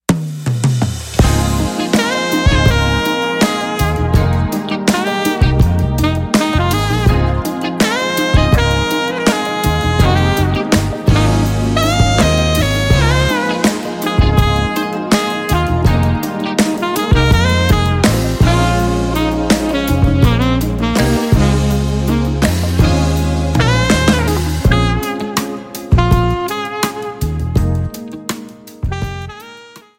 Musician, Entertainer, Saxophonist & Composer
The music of smooth jazz saxophonist